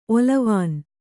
♪ olavān